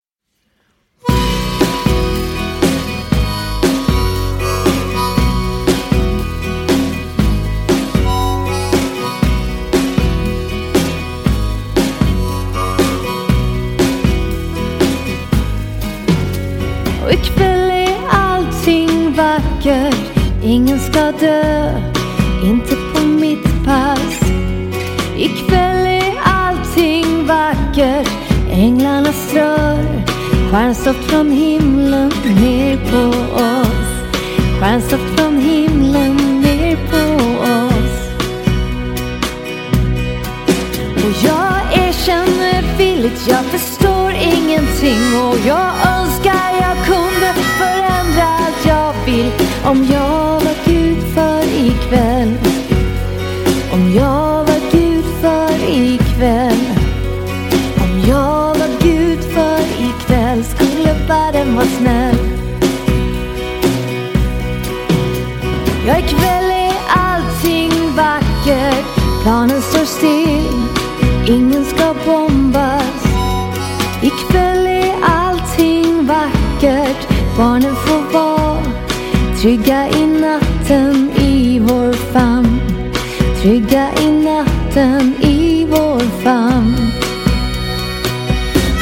trummor